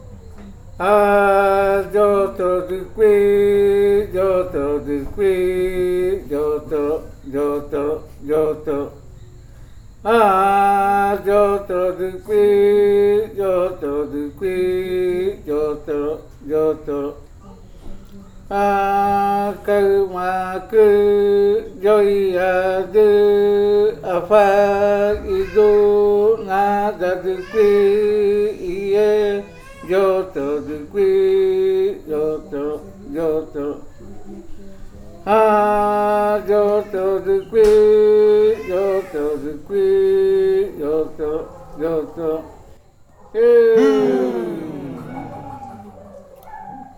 Leticia, Amazonas, (Colombia)
Grupo de danza Kaɨ Komuiya Uai
Canto fakariya de la variante Muinakɨ (cantos de la parte de abajo).
Fakariya chant from the Muinakɨ variant (Downriver chants).